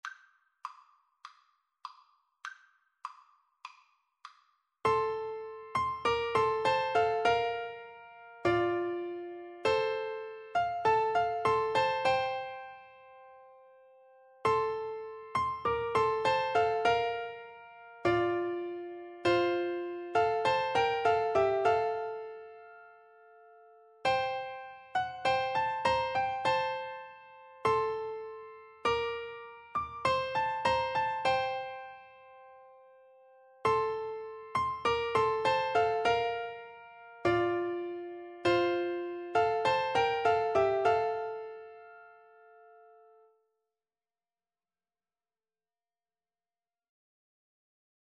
Piano Duet  (View more Easy Piano Duet Music)